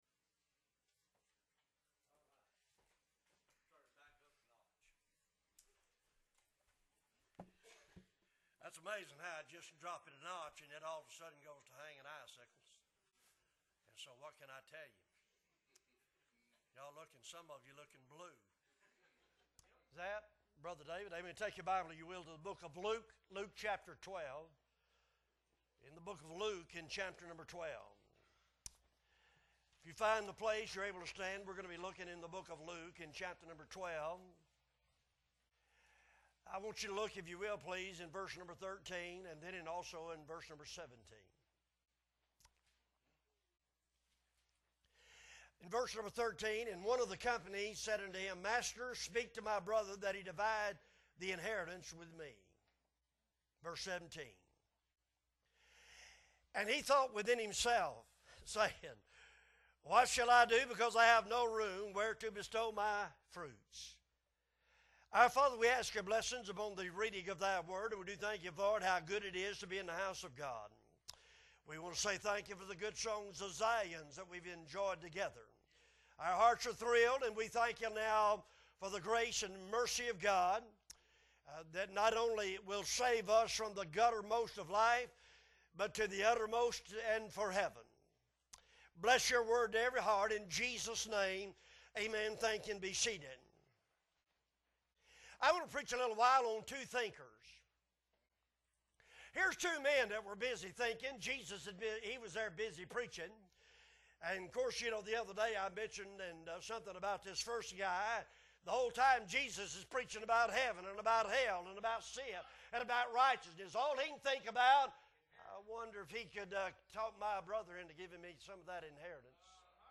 January 29, 2023 Sunday Morning Service - Appleby Baptist Church